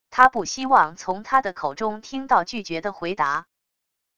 他不希望从她的口中听到拒绝的回答wav音频生成系统WAV Audio Player